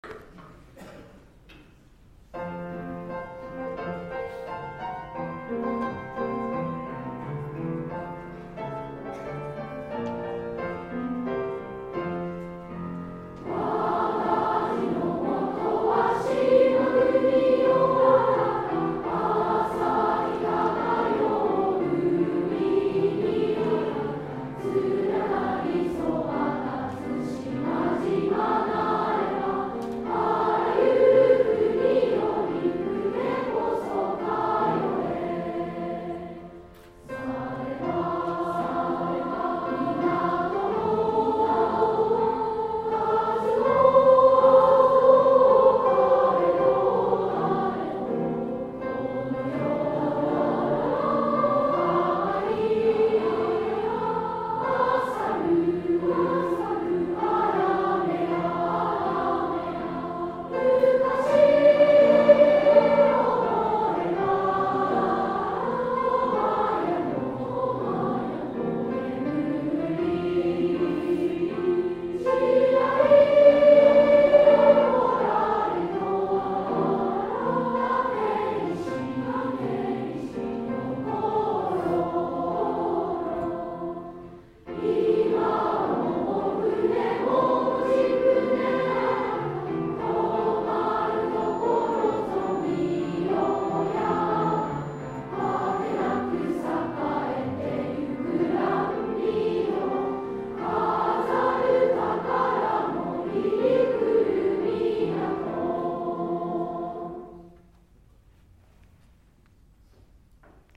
みんなの歌声市歌
作詞　森　林太郎（鴎外）
作曲　南　能衛